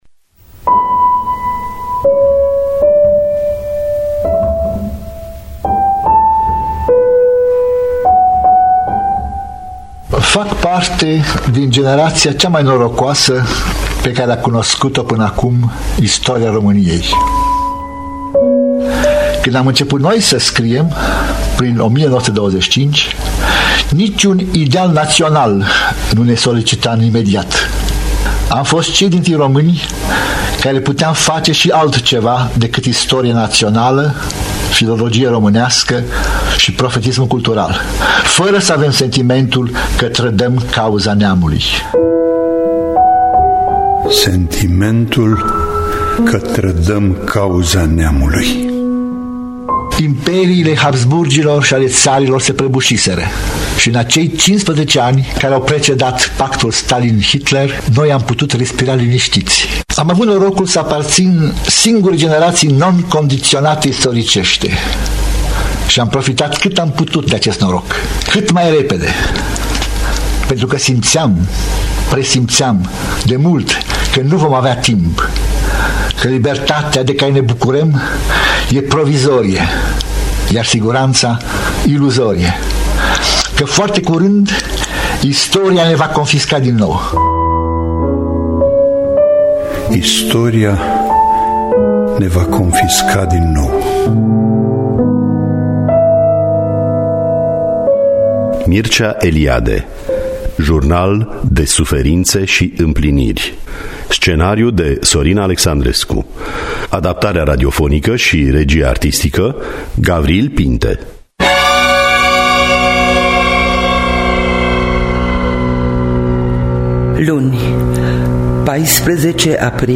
Jurnal De Suferinte Si Impliniri (2017) – Teatru Radiofonic Online
Adaptarea radiofonică